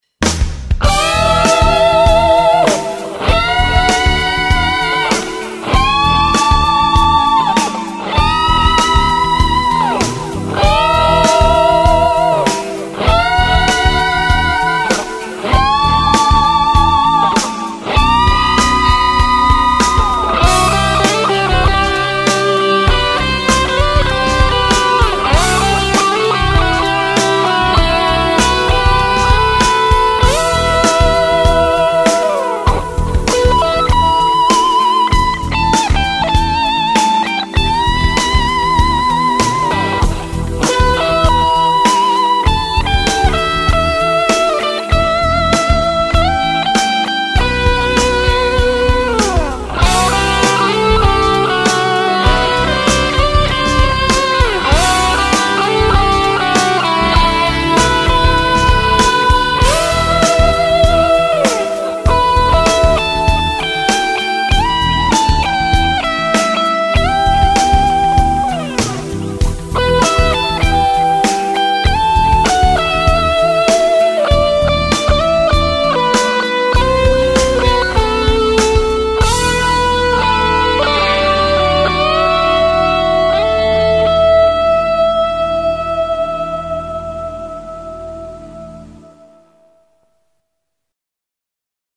also mal Spaß beiseite, ich denke schon, daß einer Erlewine etwas Zerre gut zu Gesicht steht.